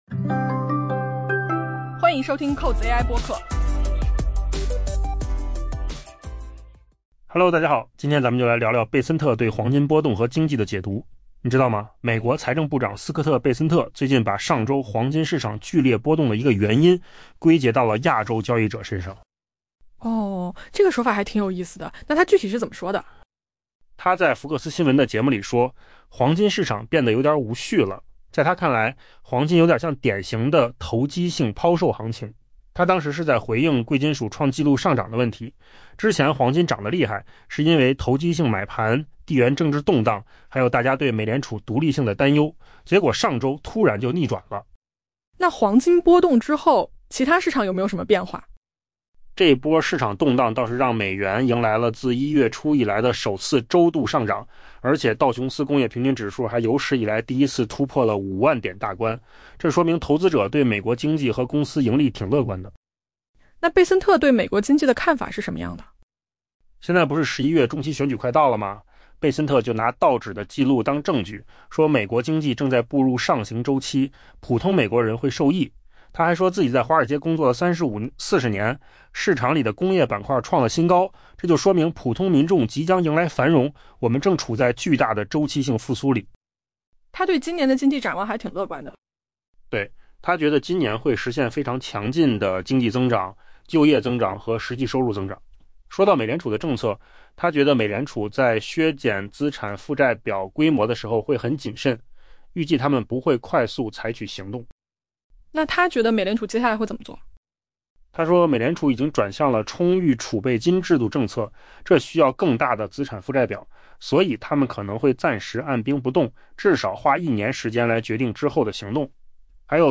AI播客：换个方式听新闻 下载mp3
音频由扣子空间生成
贝森特解读黄金波动与经济展望.mp3